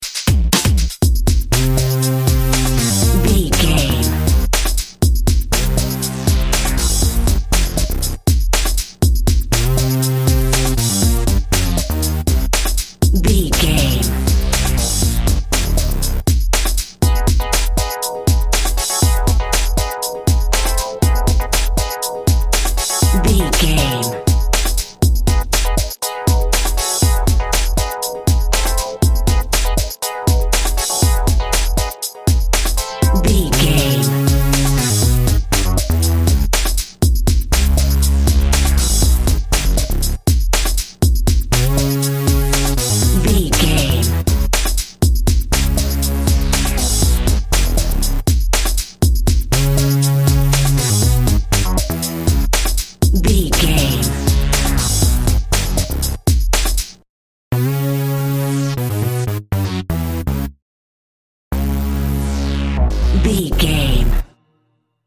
Epic / Action
Fast paced
Aeolian/Minor
C#
groovy
uplifting
futuristic
energetic
bouncy
synthesiser
drum machine
electric piano
techno
trance
synth lead
synth bass
Synth Pads